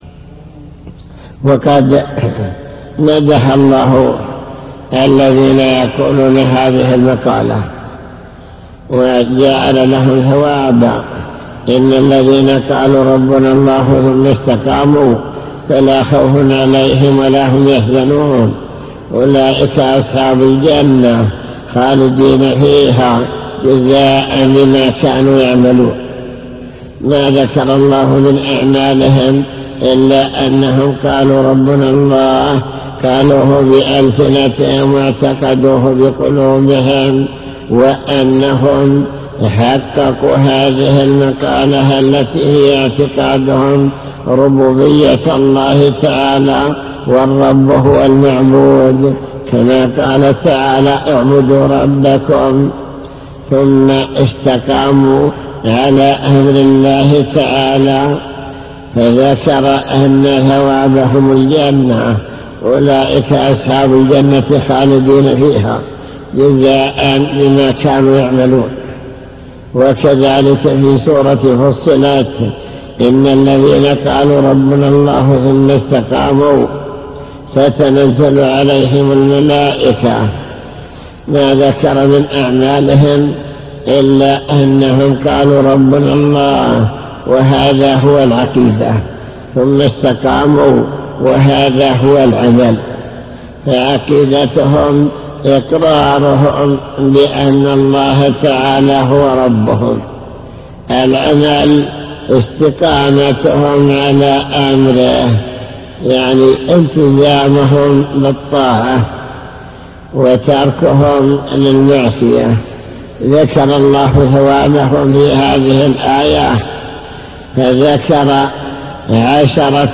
المكتبة الصوتية  تسجيلات - كتب  شرح كتاب بهجة قلوب الأبرار لابن السعدي شرح حديث قل آمنت بالله ثم استقم بشارة الله لمن آمن واستقام على طريقه